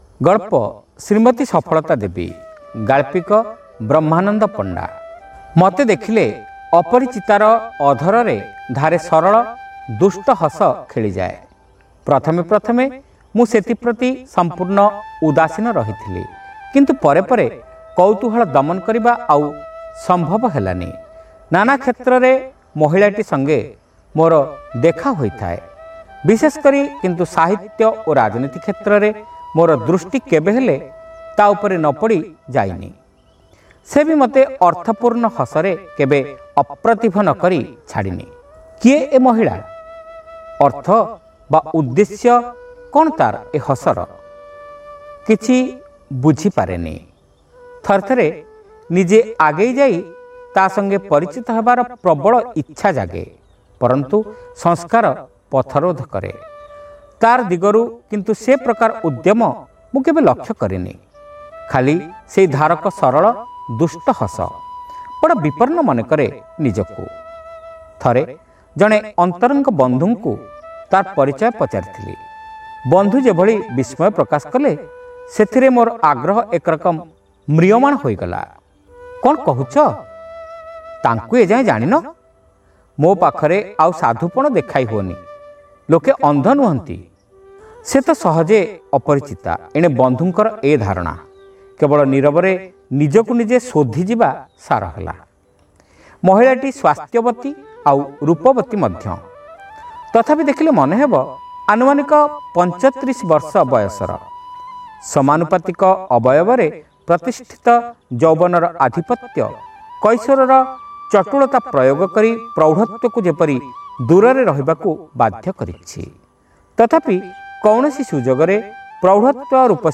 Audio Story : Srimati Safalata Devi